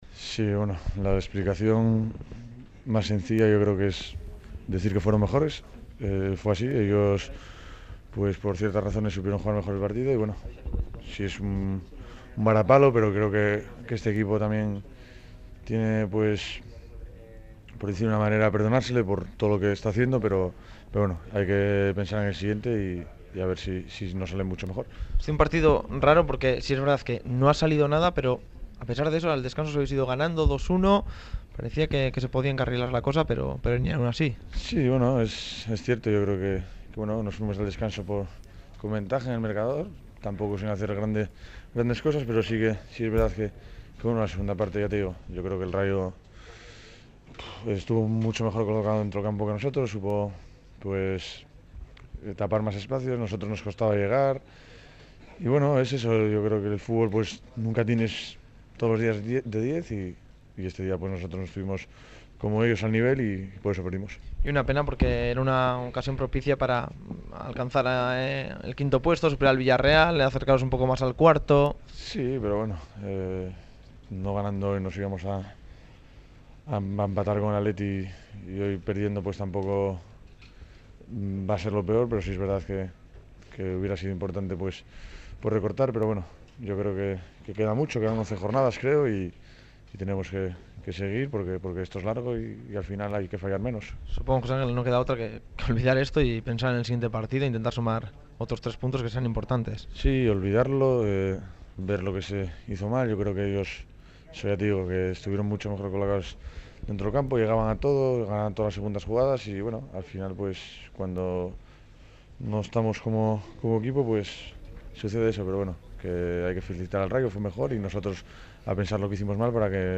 Fuera de Juego recibe a José Ángel, jugador de la Real. El equipo donostiarra ha perdido en Anoeta ante el Rayo Vallecano , en el último partido de la jornada